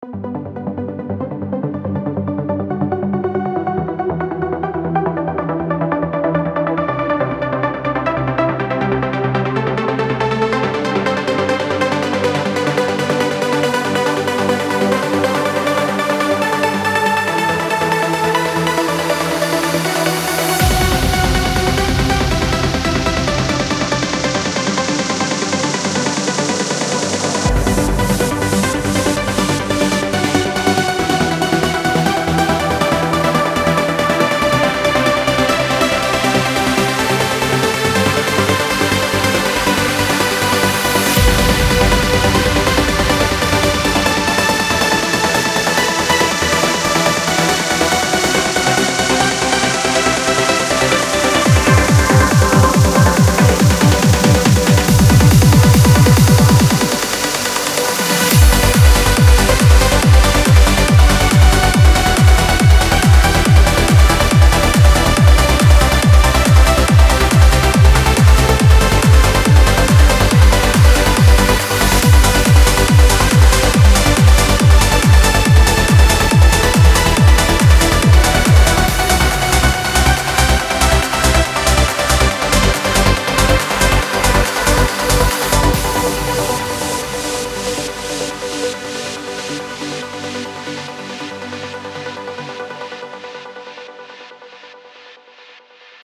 Sylenth1 мне что то больше нравится)